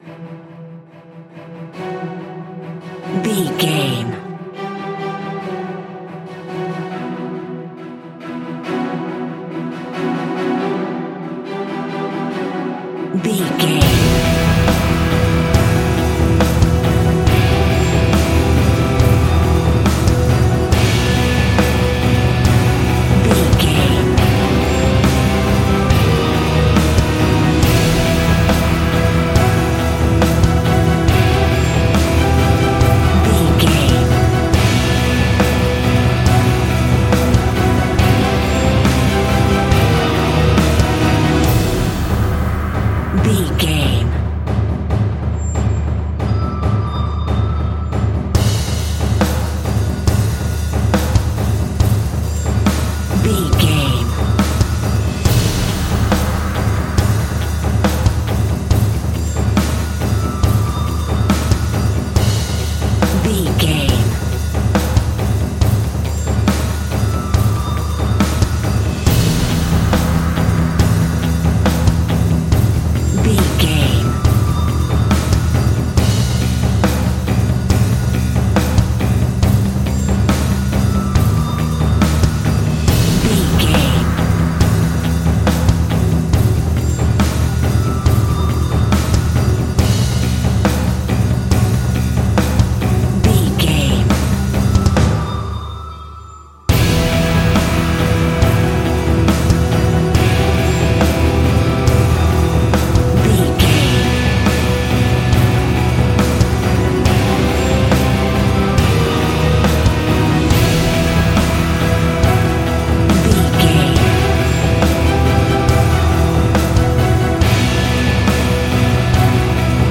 In-crescendo
Aeolian/Minor
ominous
suspense
dramatic
driving
intense
strings
drums
percussion
electric guitar
brass
cinematic
orchestral
taiko drums
timpani